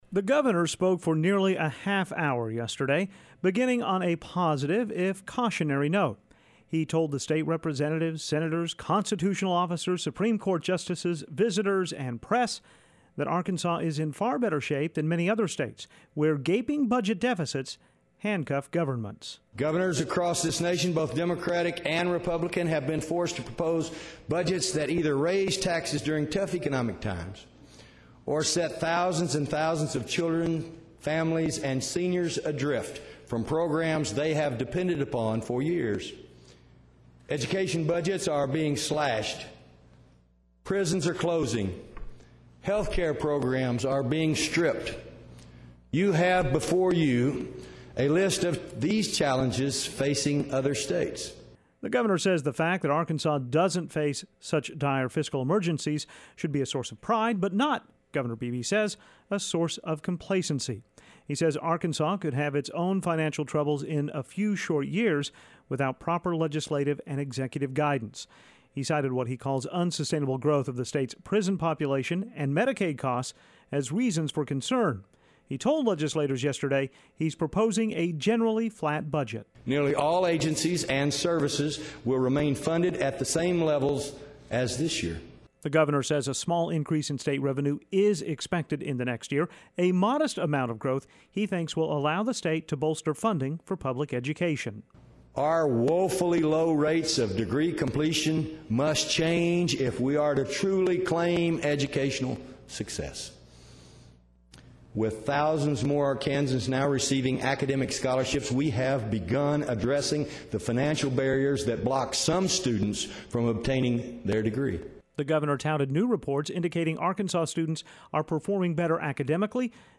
beebe address.mp3